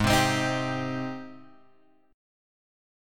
G#M7 chord {4 3 5 5 x 3} chord